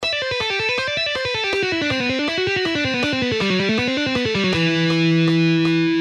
E minor Legato Run:
Original Speed:
4.-Legato-Exercise-In-Em-Scale.mp3